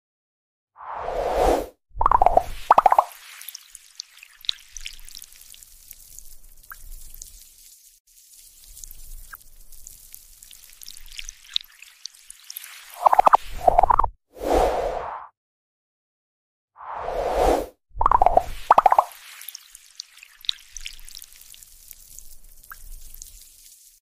Witness Ethereal Hands Release AI Generated Sound Effects Free Download
Witness ethereal hands release AI-generated digital rain! 🌧 Satisfying visuals, crisp sounds.